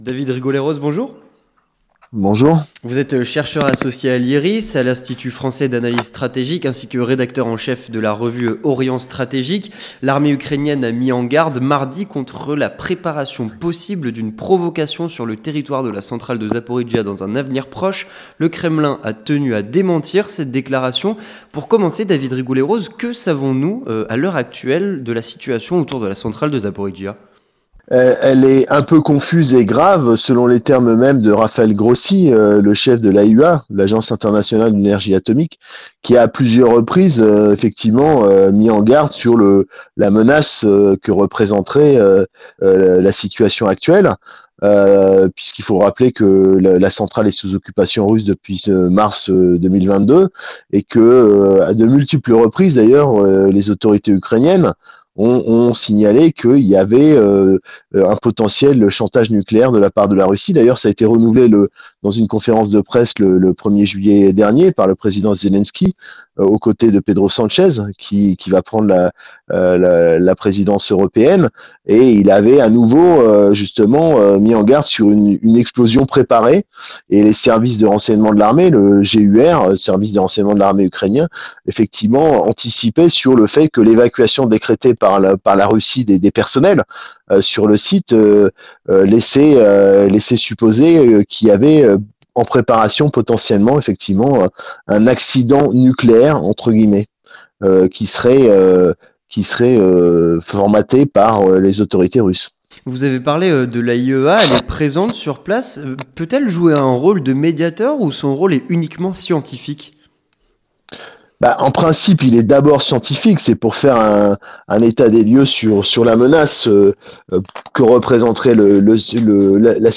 Entretien du 18h - Tensions autour la centrale de Zaporijia